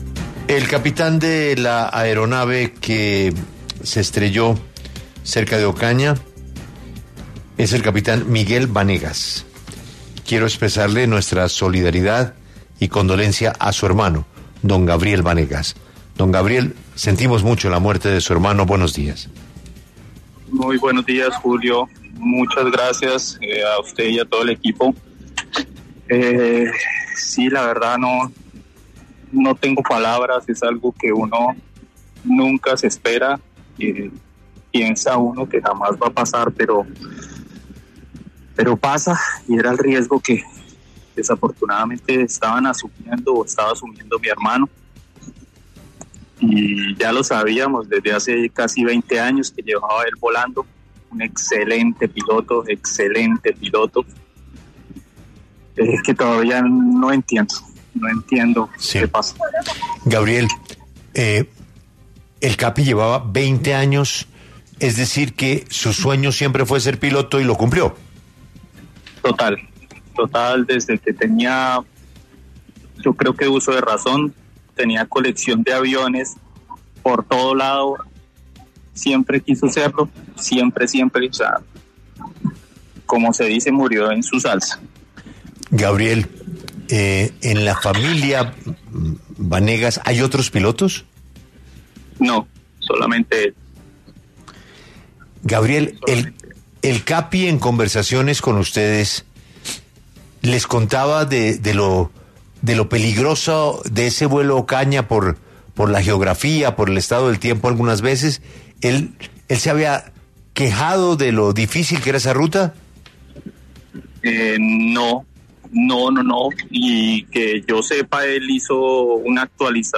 pasó por los micrófonos de 6AM W, con Julio Sánchez Cristo, para hablar sobre la tragedia.